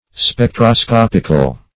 \Spec`tro*scop"ic*al\, a.